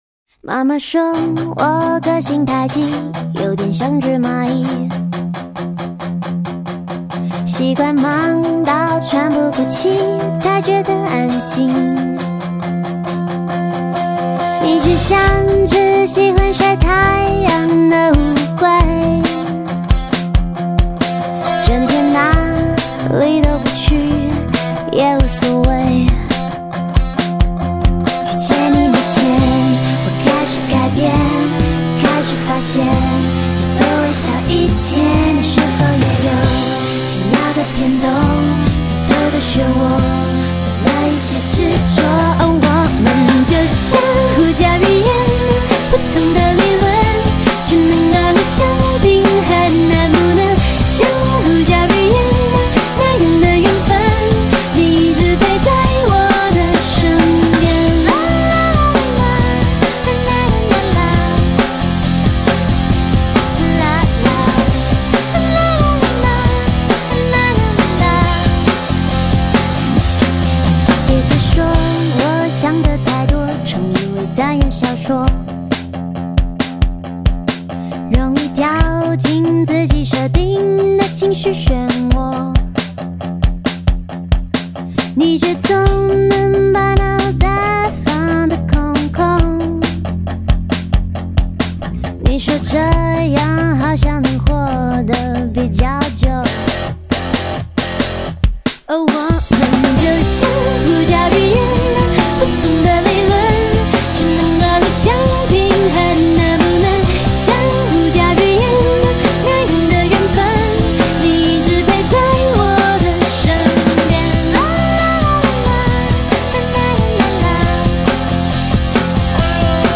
回归最天然的最纯粹的风格
舍弃繁华的计算机音色和包装，如何简化一切，回归最天然的最纯粹的。